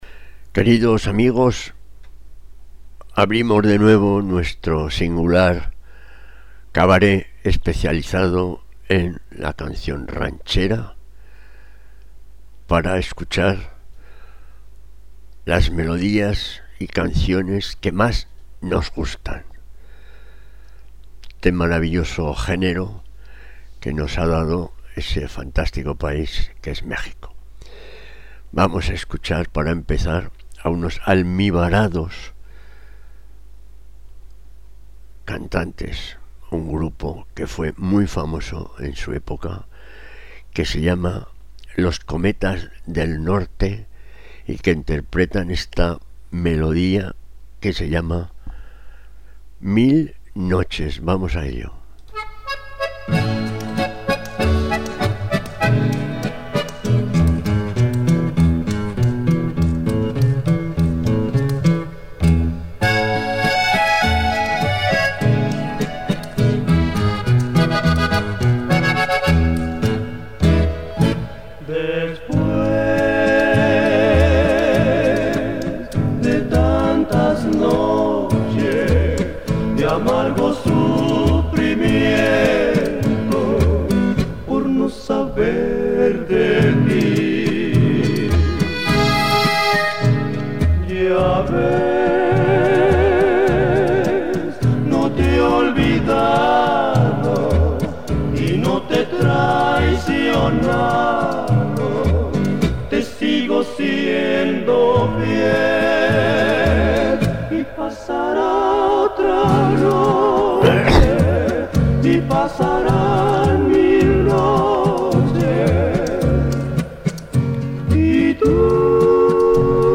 comenta un recital de este género